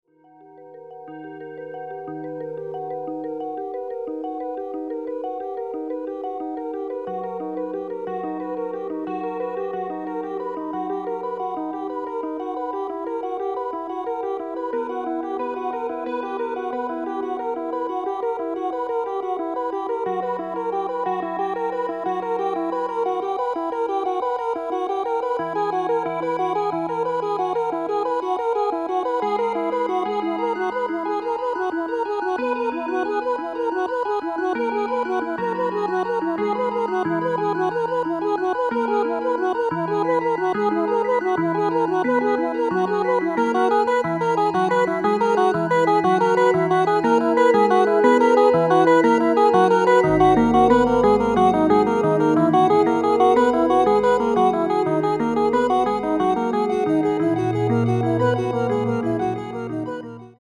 Electronic tape
consists entirely of modifications of the voice of soprano